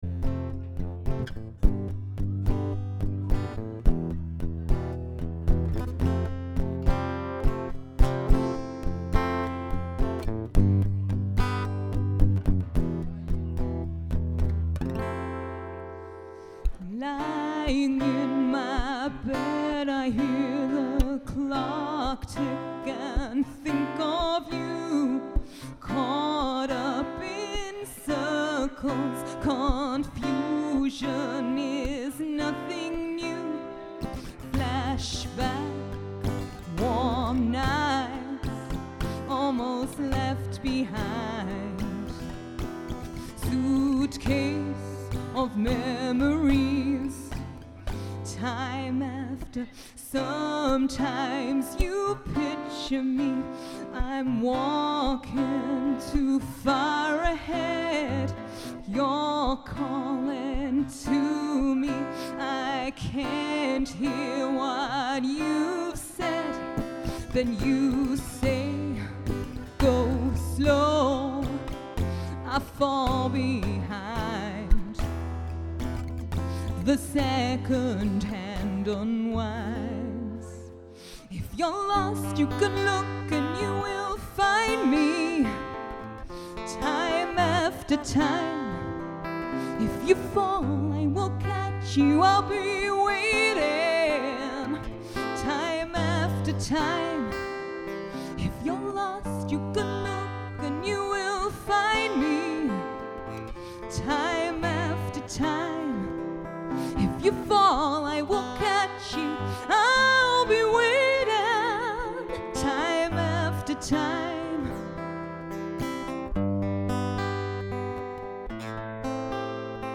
Live recording with Guitarist